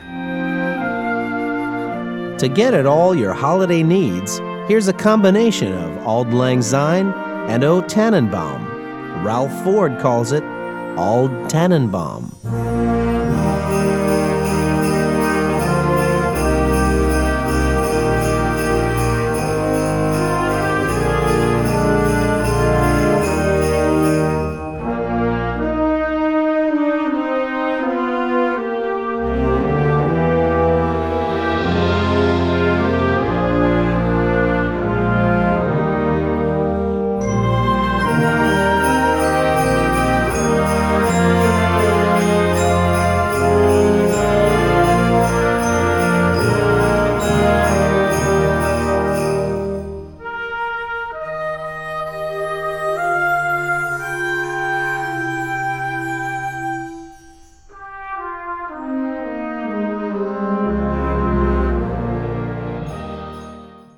Besetzung: Blasorchester
soothing setting